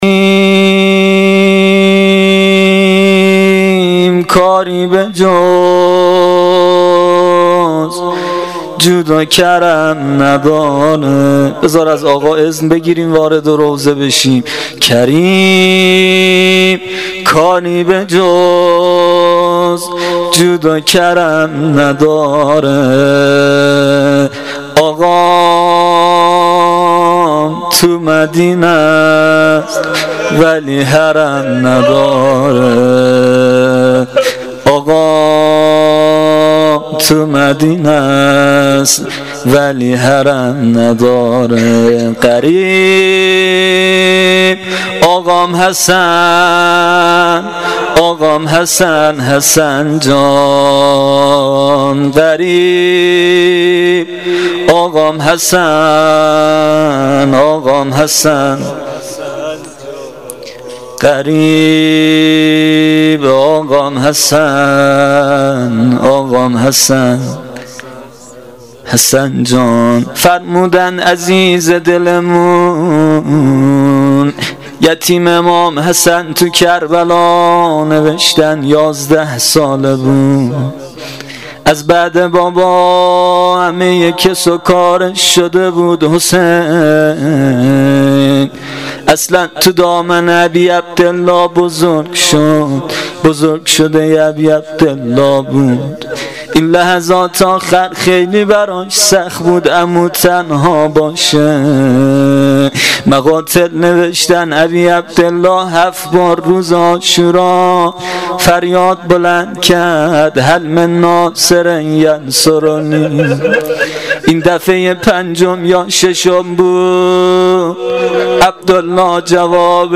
روضه شب پنجم محرم الحرام 1396
هیئت جواد الائمه, شهرستان کمیجان